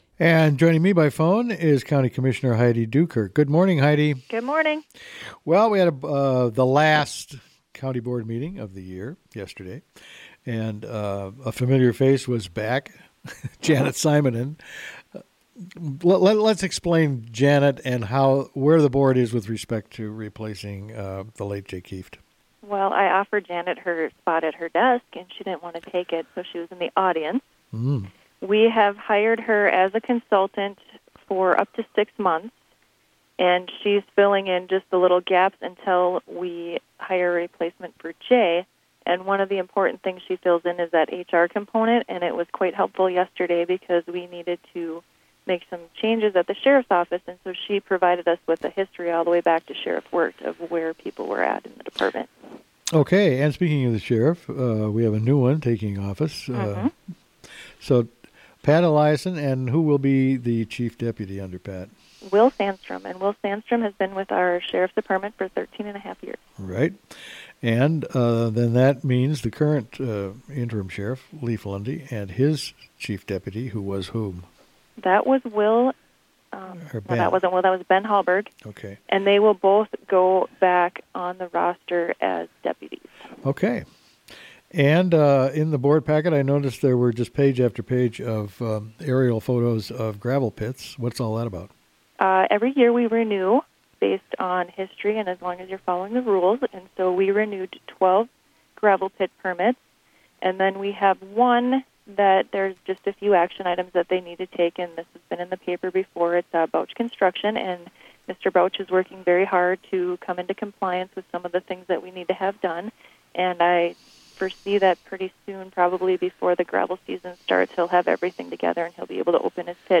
spoke with Commissioner Heidi Doo-Kirk.